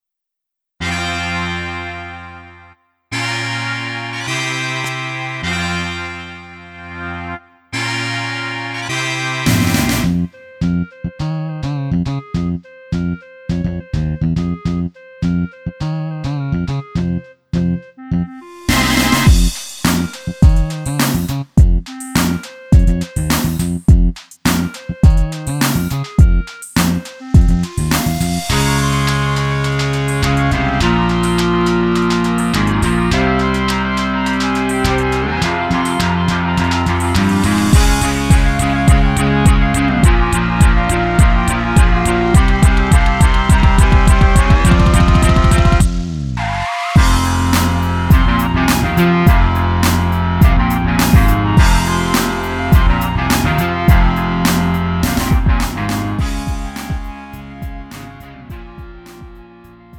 축가, 웨딩, 결혼식 MR. 원하는 MR 즉시 다운로드 가능.
음정 -1키 장르 가요
Lite MR